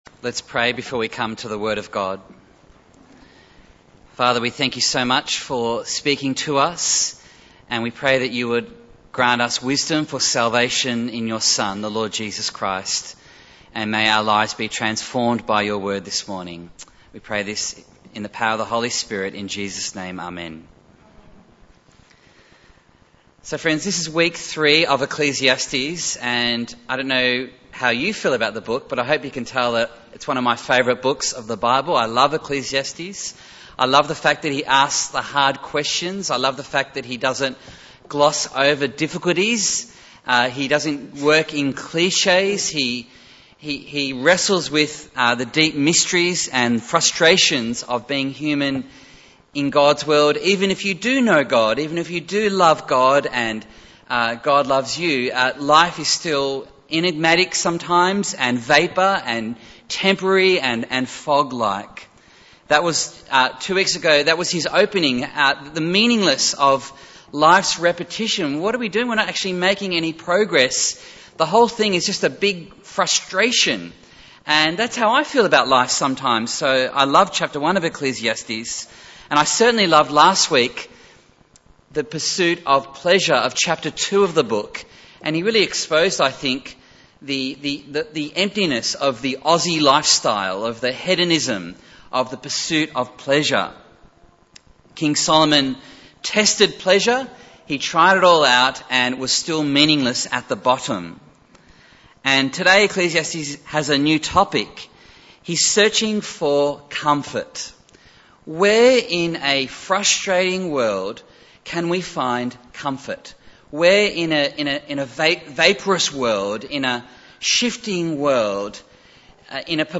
Bible Text: Ecclesiastes 4:1-16 | Preacher